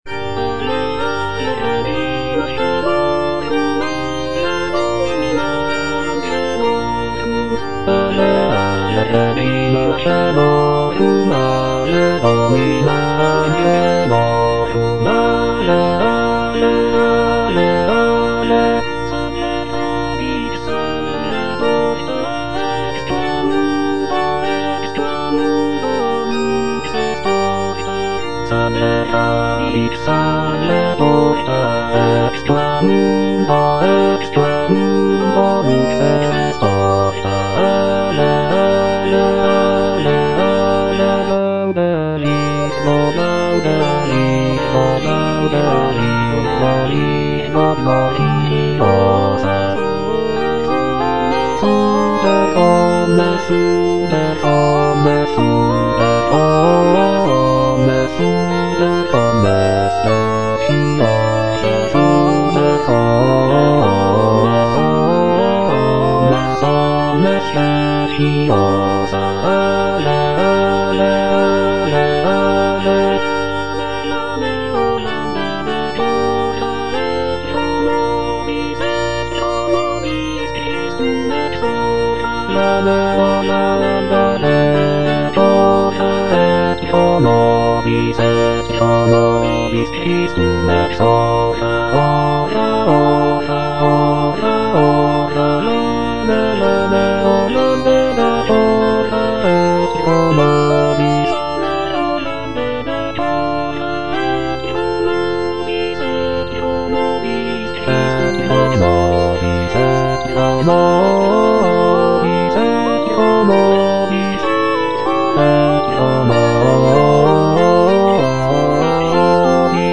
I. LEONARDA - AVE REGINA CAELORUM Bass (Emphasised voice and other voices) Ads stop: auto-stop